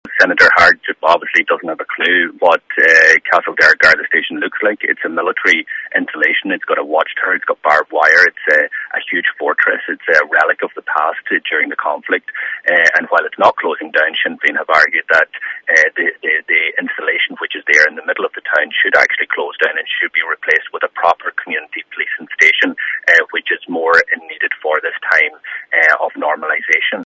And, in comparing the closure of rural Garda Stations in Donegal to the closure of PSNI stations in the North, Deputy Doherty said Senator Harte is clueless…